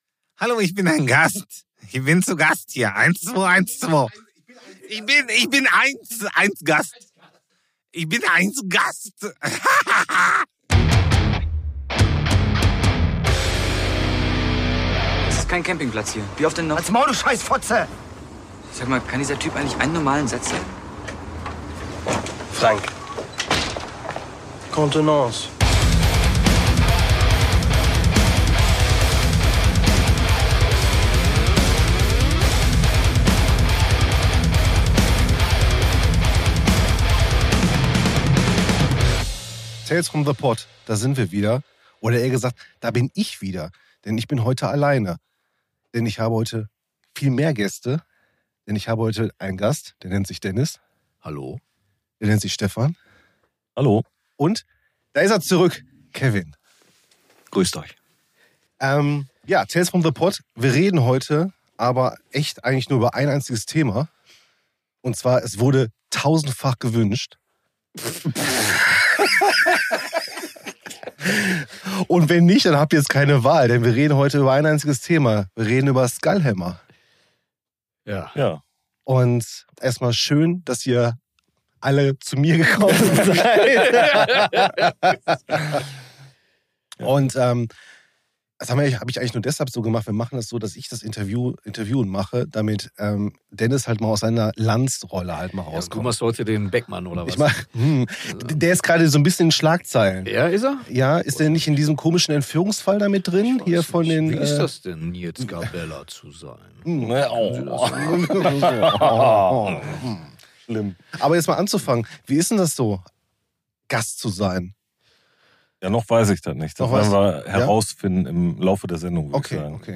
#43: Wo die Schädel sich stapeln! (im Gespräch mit: SKULLHAMMER) ~ TALES FROM THE POTT Podcast